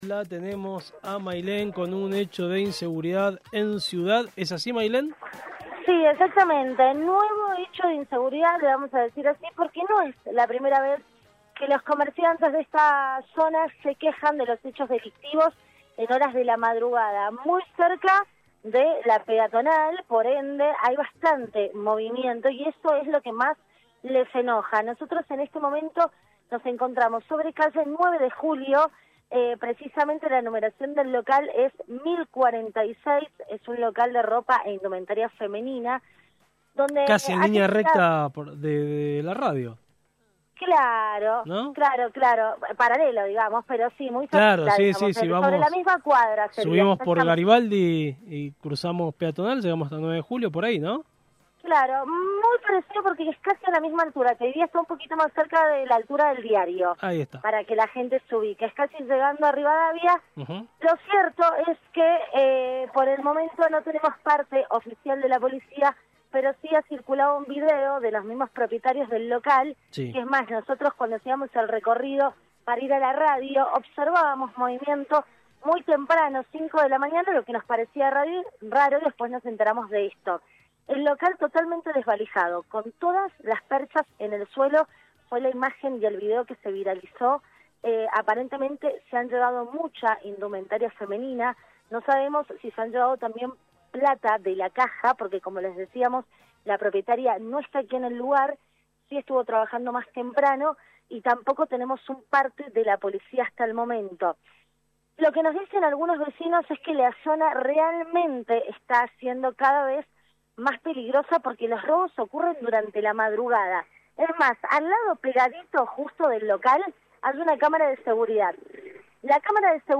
LVDiez - Radio de Cuyo - Móvil de LVDiez desde Microcentro